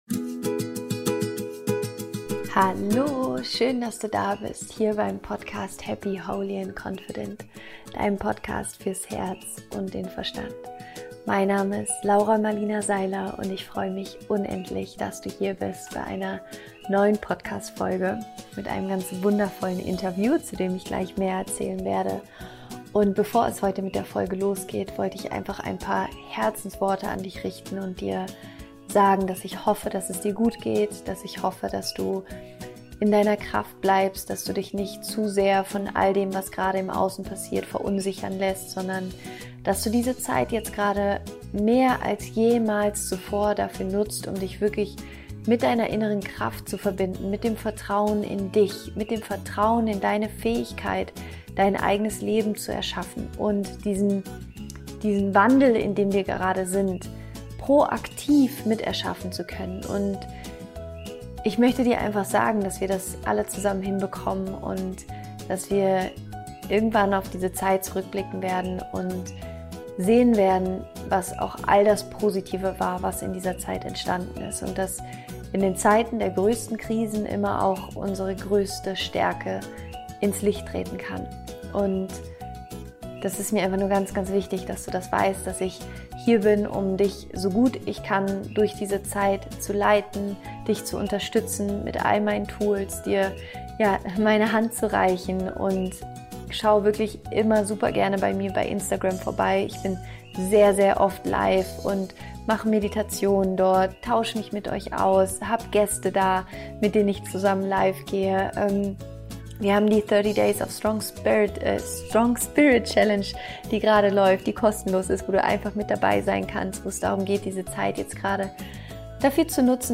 Ernährung ist eine Entscheidung – Interview Special